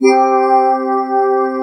Index of /90_sSampleCDs/Best Service Dream Experience/SYN-PAD